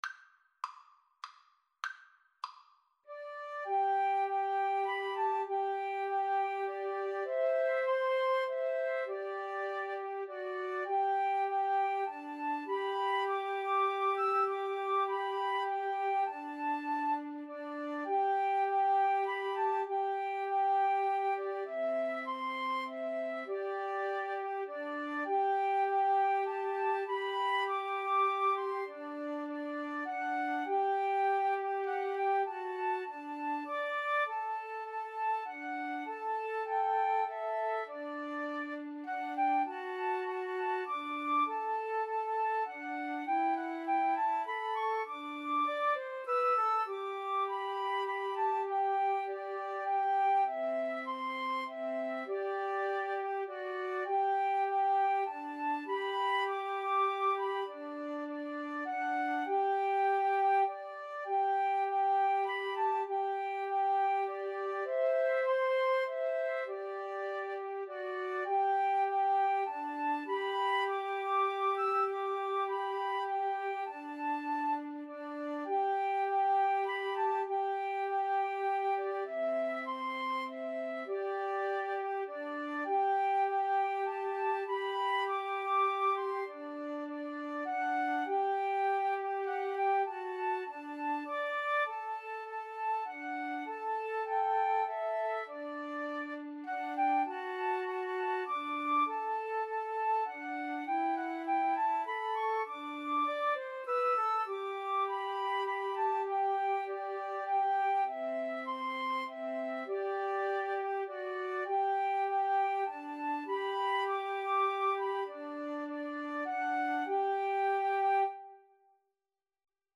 G major (Sounding Pitch) (View more G major Music for Flute Trio )
Moderato
3/4 (View more 3/4 Music)
Flute Trio  (View more Easy Flute Trio Music)
Classical (View more Classical Flute Trio Music)